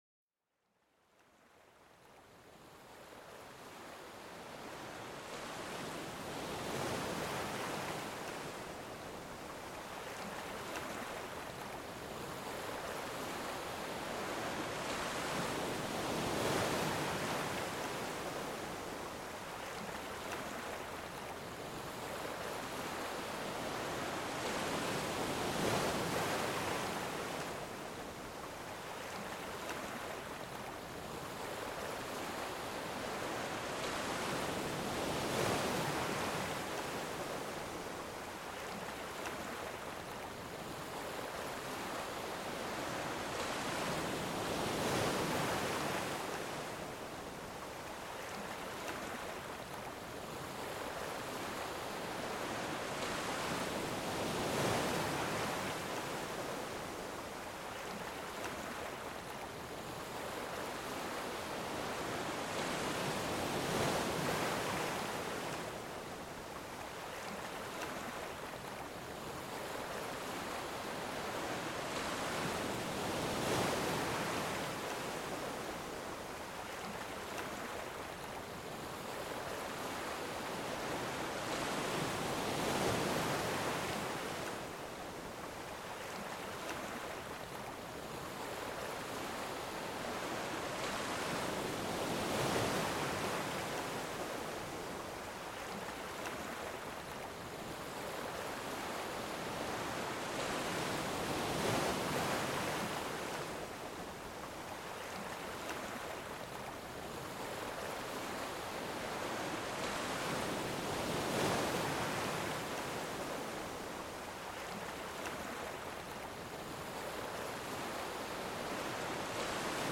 Laissez-vous bercer par le son apaisant des vagues qui viennent doucement caresser le rivage. Chaque flux et reflux crée une harmonie naturelle qui invite à la détente et à l’évasion.